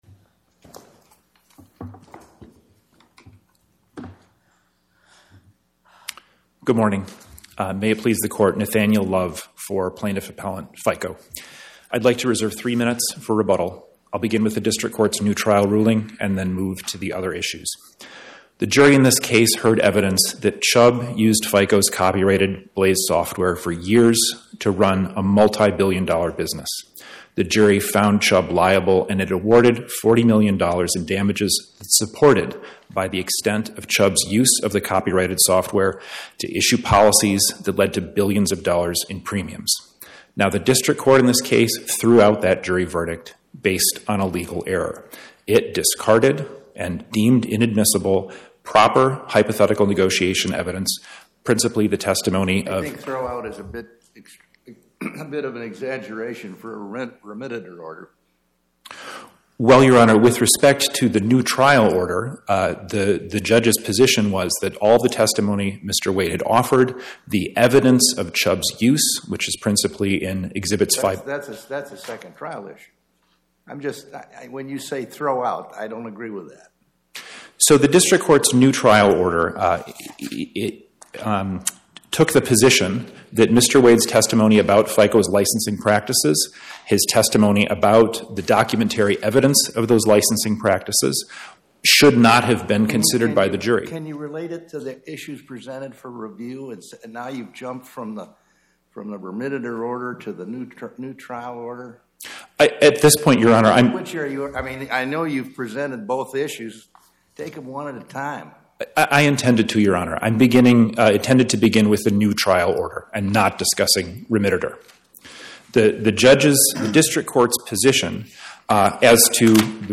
My Sentiment & Notes 25-1340: Fair Isaac Corp. vs Federal Insurance Co. Podcast: Oral Arguments from the Eighth Circuit U.S. Court of Appeals Published On: Thu Dec 18 2025 Description: Oral argument argued before the Eighth Circuit U.S. Court of Appeals on or about 12/18/2025